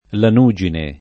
vai all'elenco alfabetico delle voci ingrandisci il carattere 100% rimpicciolisci il carattere stampa invia tramite posta elettronica codividi su Facebook lanugine [ lan 2J ine ] (raro lanuggine [ lan 2JJ ine ]) s. f.